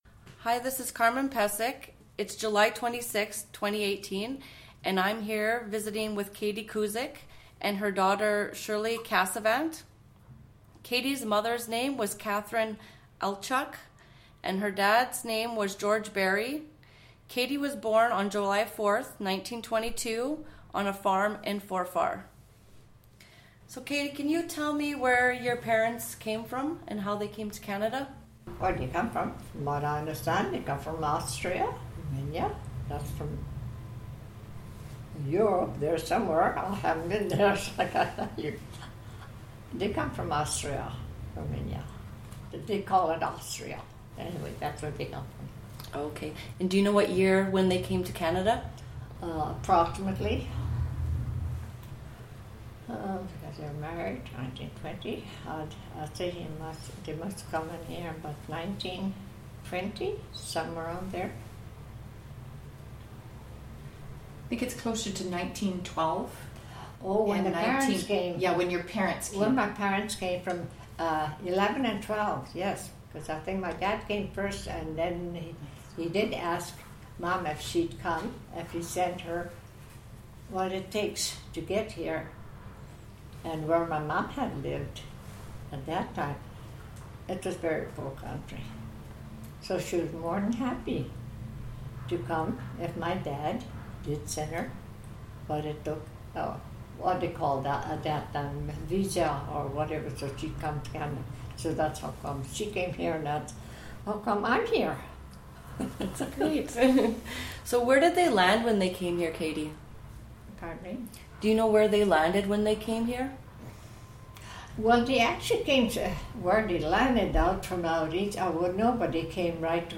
Audio interview, transcript of audio interview and obituary,